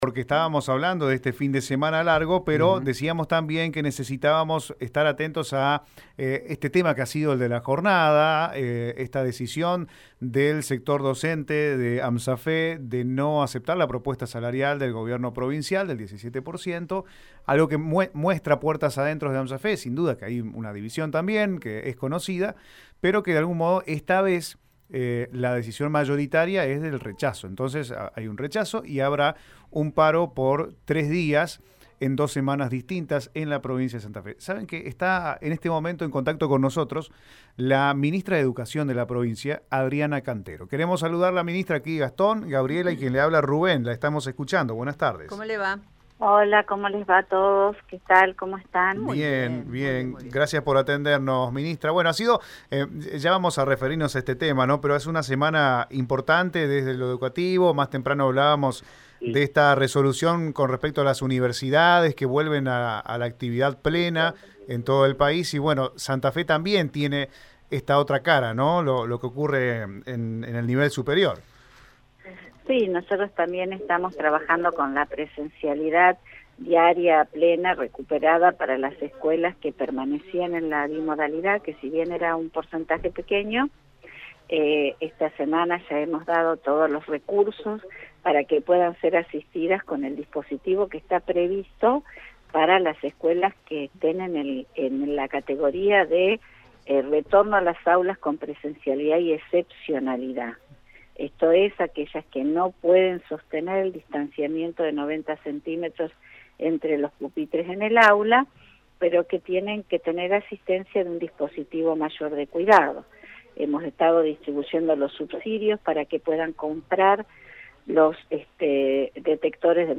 En diálogo con Radio EME, La ministra de Educación de Santa Fe, Adriana Cantero, comentó respecto al rechazo de la propuesta por parte de AMSAFE y la decisión de tomar medidas de fuerza.